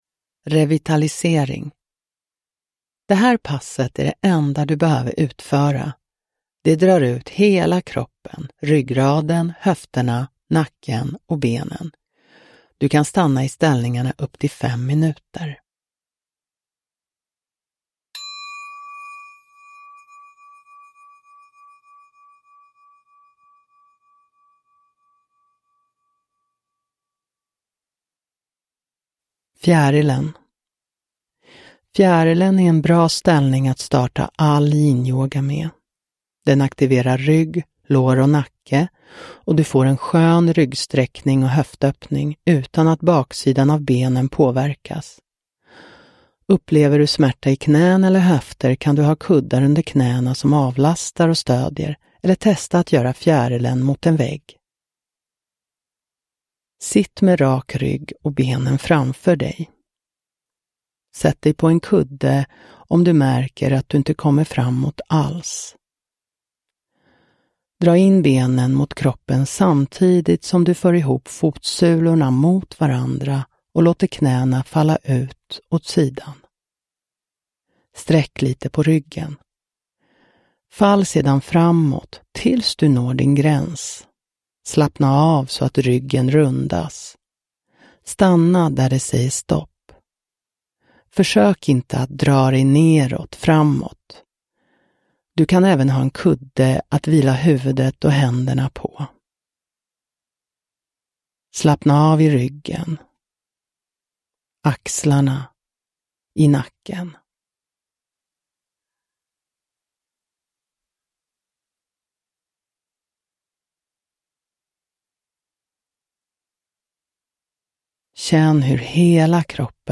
Revitalisering – Ljudbok – Laddas ner